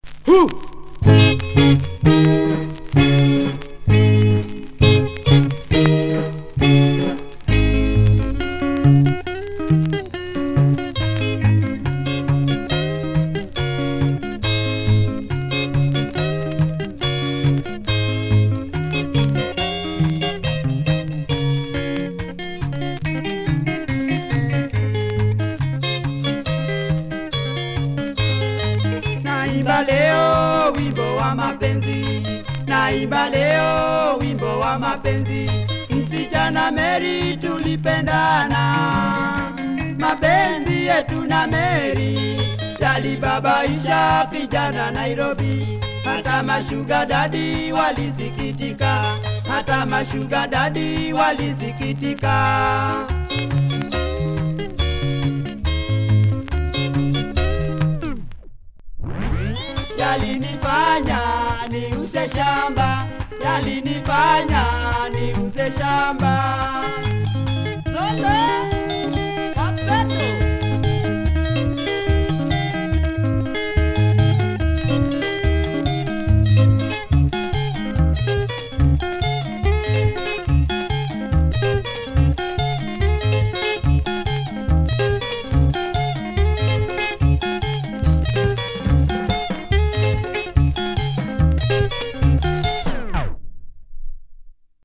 Tight Kikamba Benga.